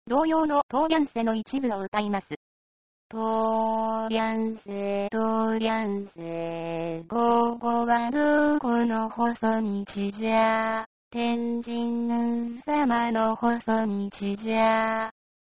音声読み上げソフト
それでは、どんな感じでしゃべってくれるか聞いて見ましょう！
なんと歌も歌います。
コテコテのガチガチの機械音。。。
慣れるとなかなかの美声に聞こえてきますよ～！！